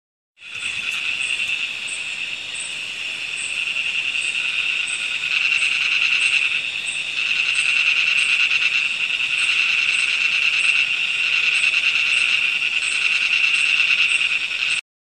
Play call
The call is a monotonous rasping sound.
ingerophrynus-parvus.mp3